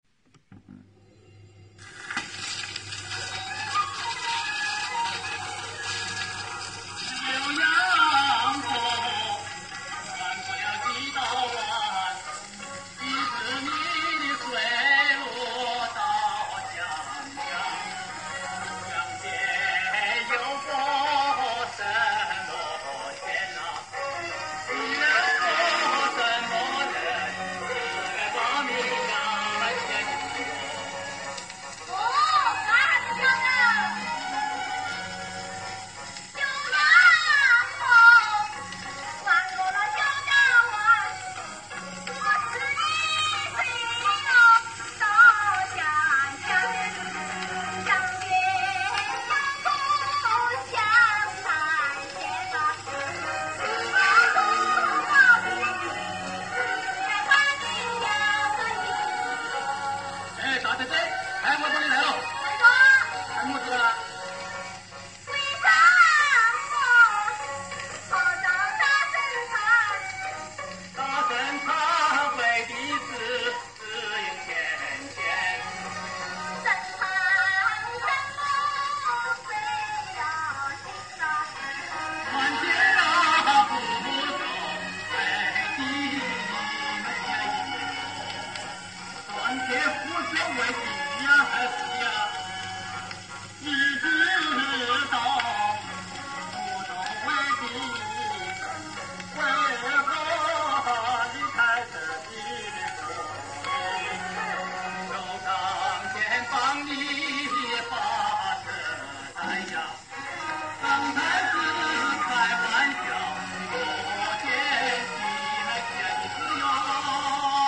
[19/3/2021]湖南民歌《浏阳河》前身 - 花鼓戏《双送粮》选段早期演唱版（1951年）